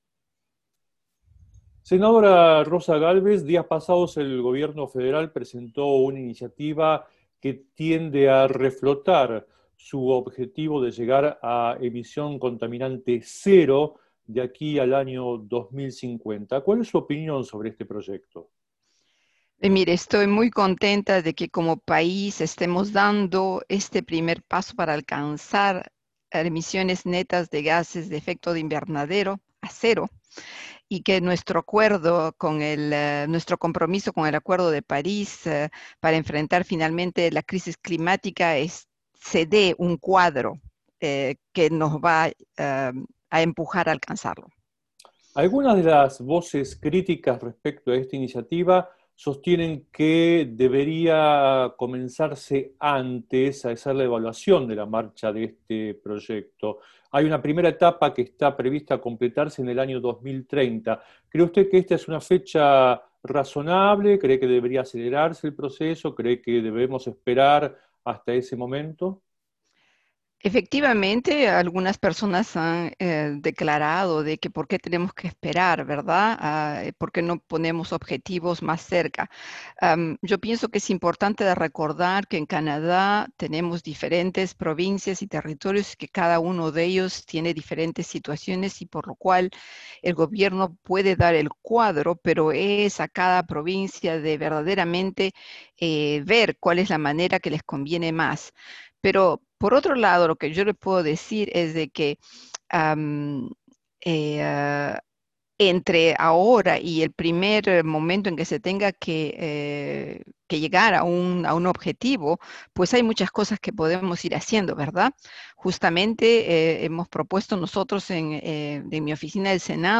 Escuche la entrevista con la senadora Rosa Gálvez: La iniciativa presentada días atrás por el gobierno federal canadiense, para alcanzar la meta de emisiones contaminantes cero en el año 2050, es un paso adelante en la consecución de los compromisos asumidos por el país en el pasado en la materia.